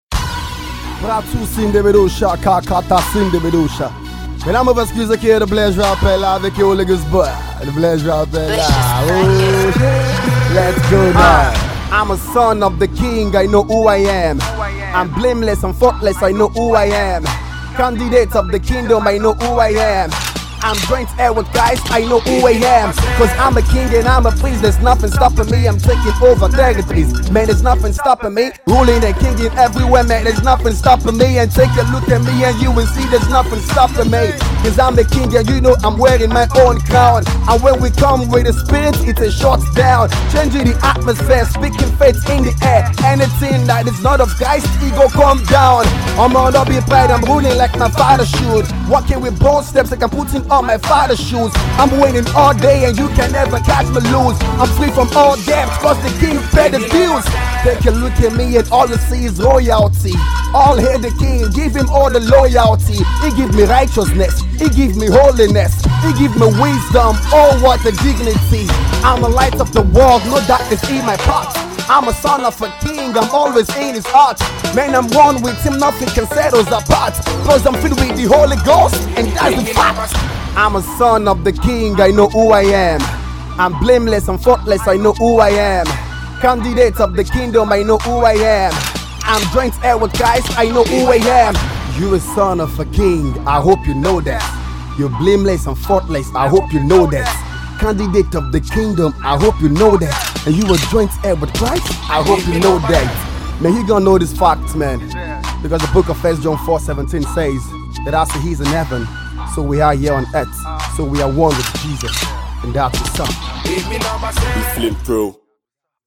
Gospel Rapper